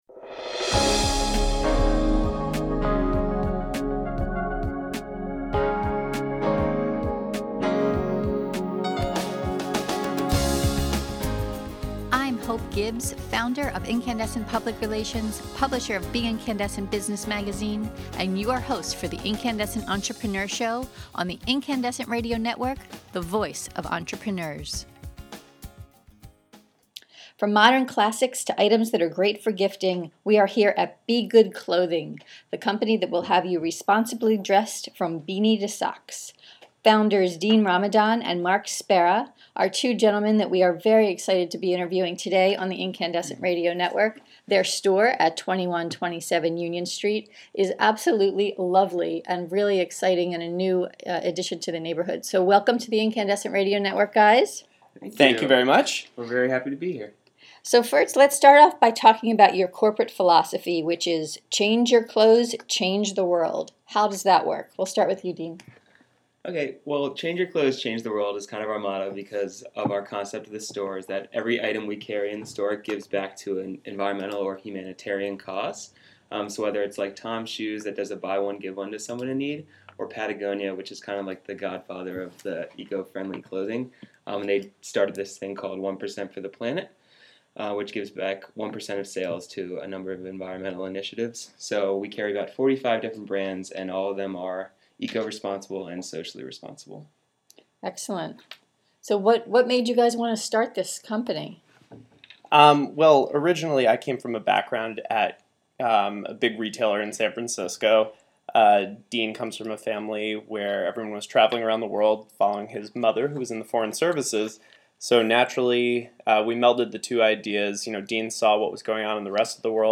In this podcast interview, you’ll learn: What their corporate philosophy truly means: “Change your clothes.